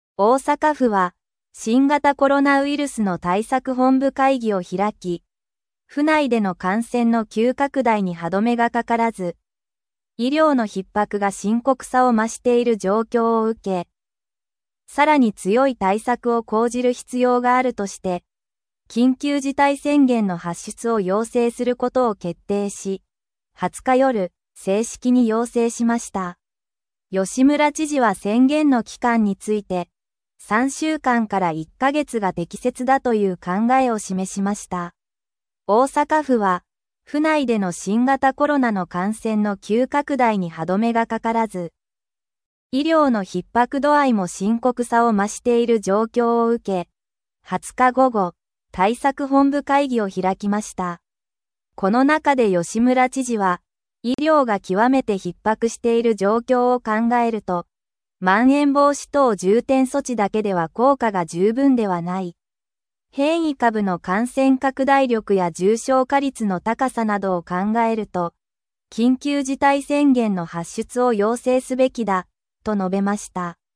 このニュースの練習用の音源はこちら⇊です（実際のアナウンサーの声ではなくコンピューターによる音読です）。